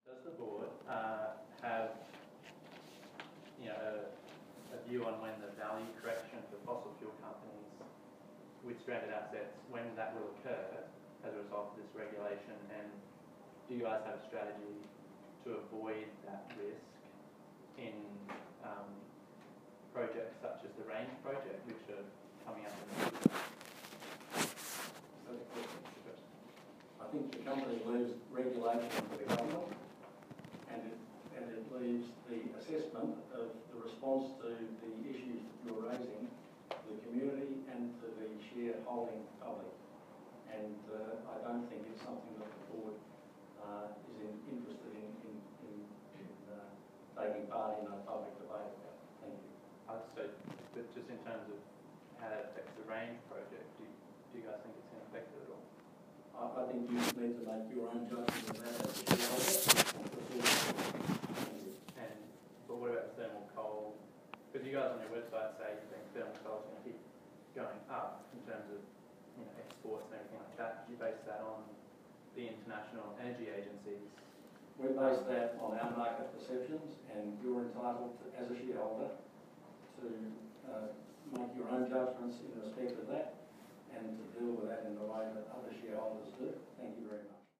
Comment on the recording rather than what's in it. Given the dire predictions for the future of the thermal coal industry, a shareholder attending Stanmore’s annual general meeting today asked the board how the company planned to avoid massive losses in the face of a global transition away from coal-fired power generation.